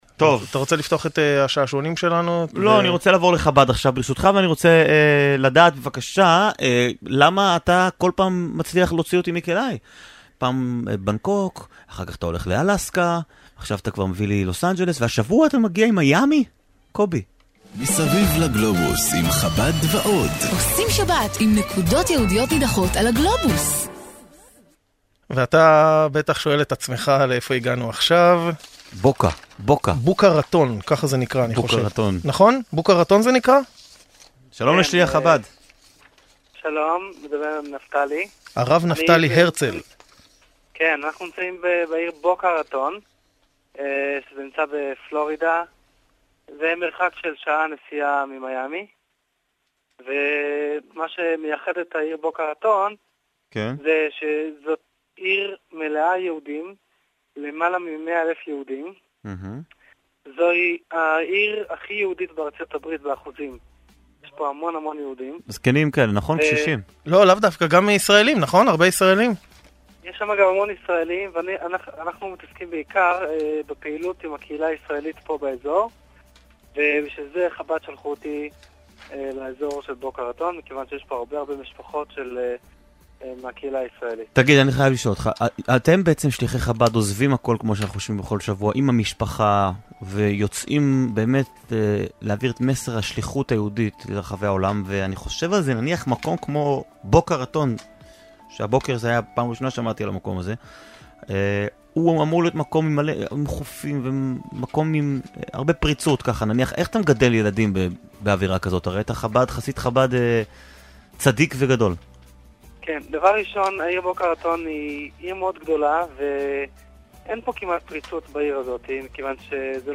ד"ש חב"די מפלורידה ברדיו תל-אביב ● להאזנה
המשודרת מידי יום שישי ברדיו תל אביב